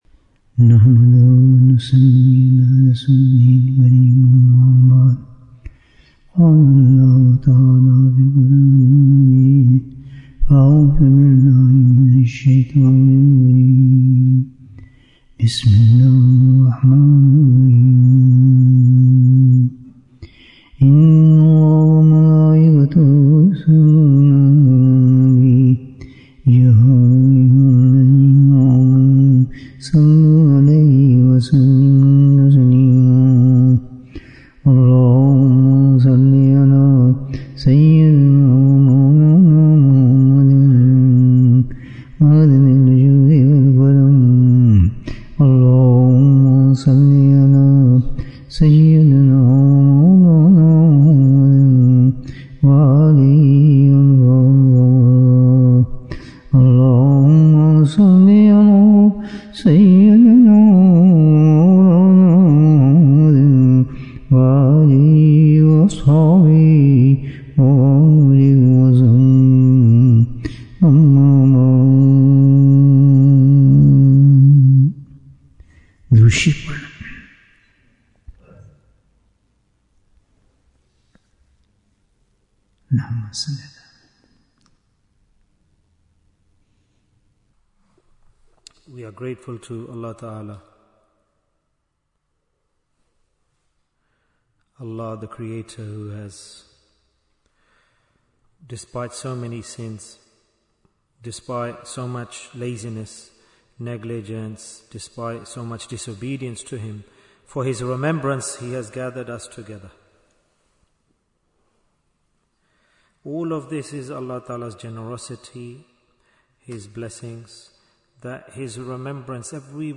What is the Greatest Ibaadah? Bayan, 89 minutes11th December, 2025